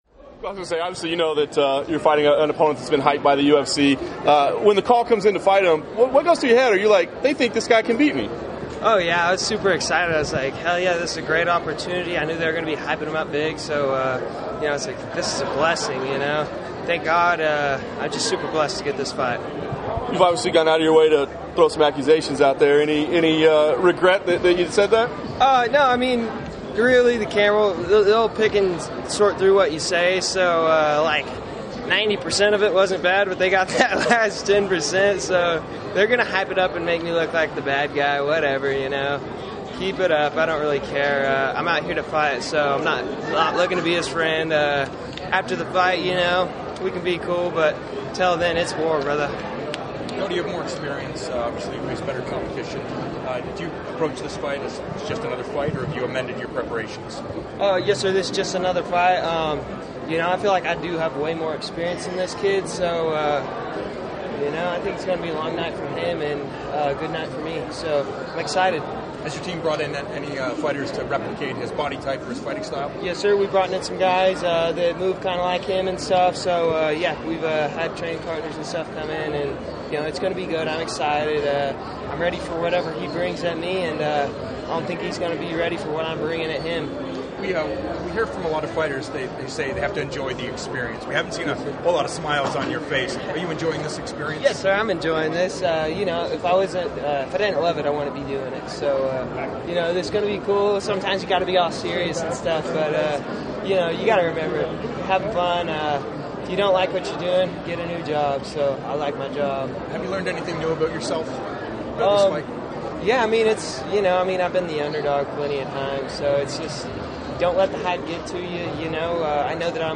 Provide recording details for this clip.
talks to the assembled press after his UFC Vegas open workout session at the MGM Grand in Las Vegas.